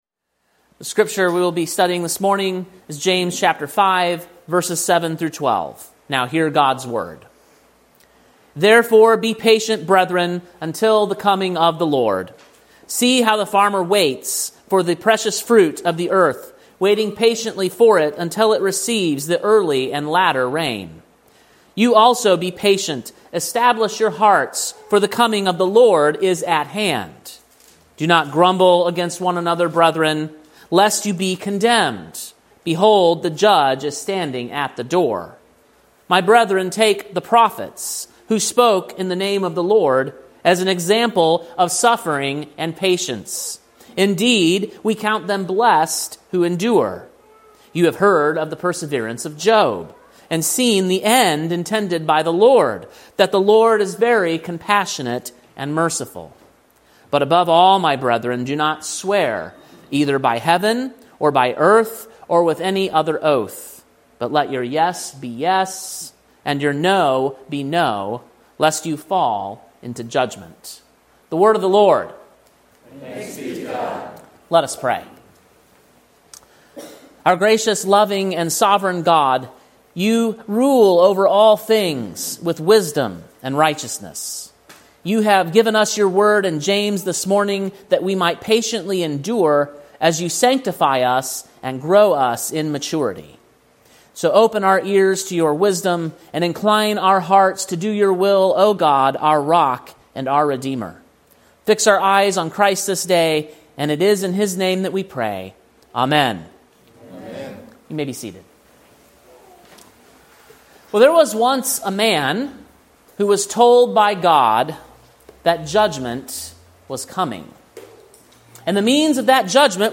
Sermon preached on April 27, 2025, at King’s Cross Reformed, Columbia, TN.